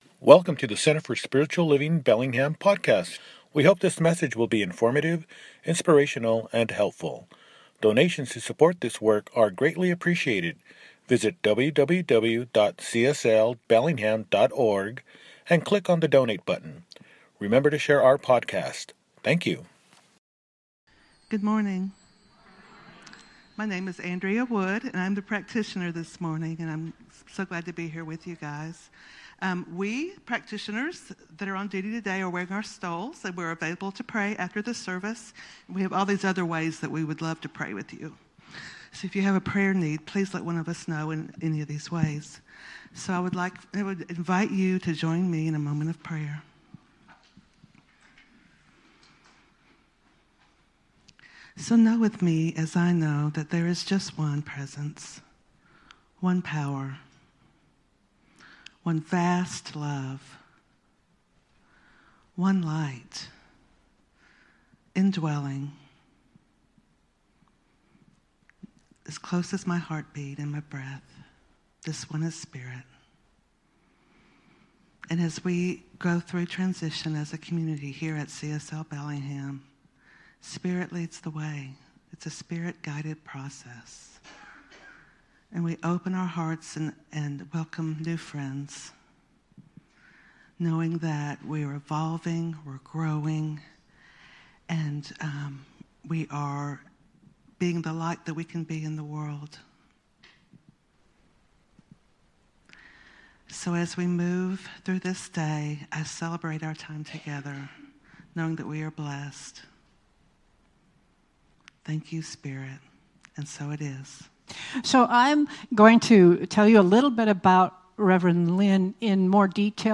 Tasting Sweetness – Celebration Service